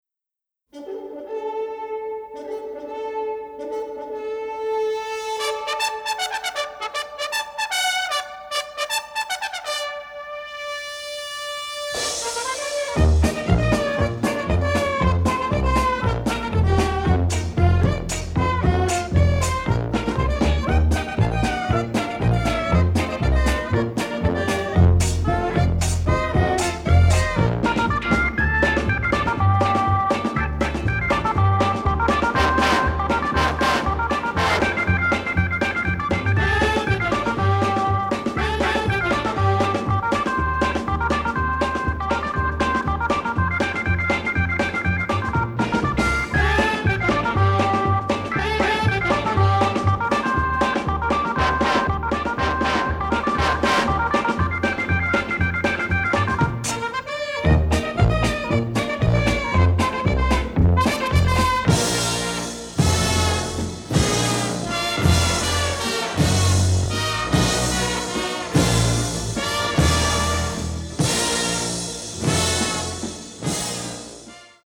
BONUS TRACKS (Mono)